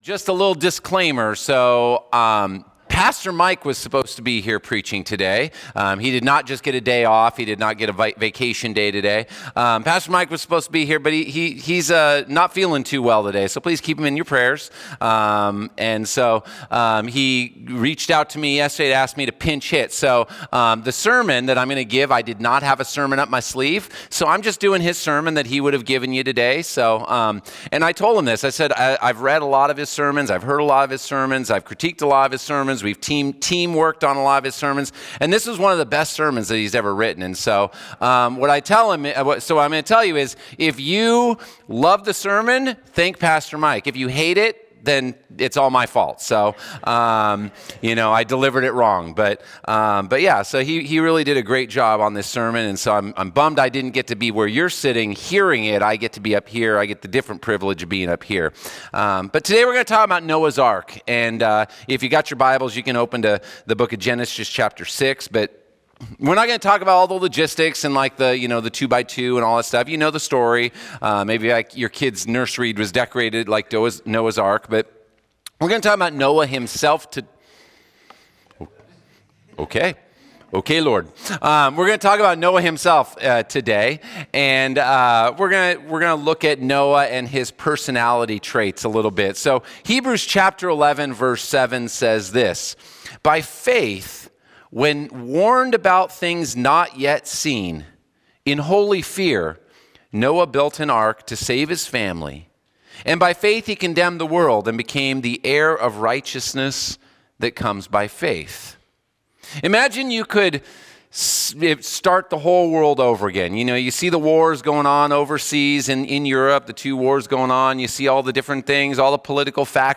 01-26-Sermon.mp3